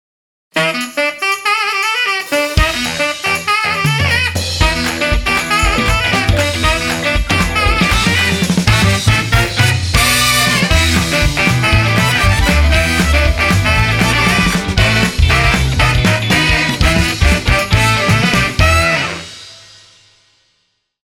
TVCM
INSTRUMENTAL
BIG BAND / JAZZ
キレのあるサックスのフレーズを中心に、リズムに躍動感を持たせることで、
スタイリッシュでありながら、しっかり耳に残るサウンドを目指した楽曲です。